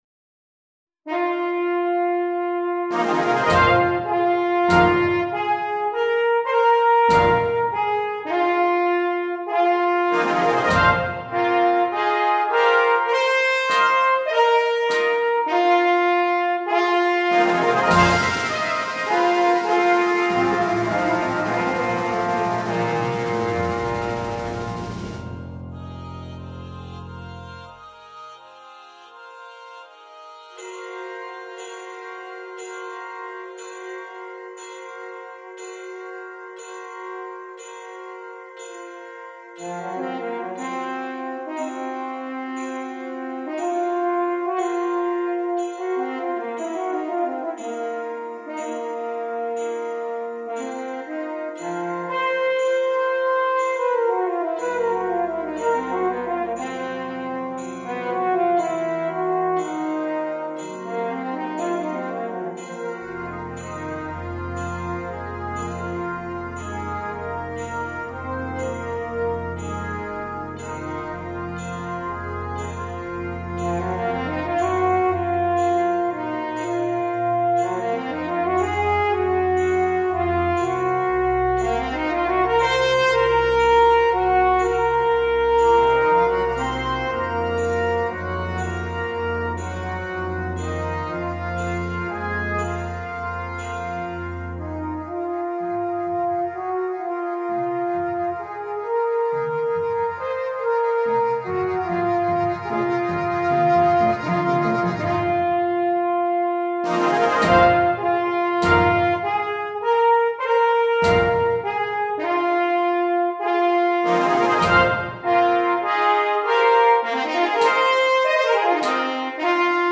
Mit klanglichen Nuancen traditioneller japanischer Musik
Besetzung: Eb Horn Solo & Brass Band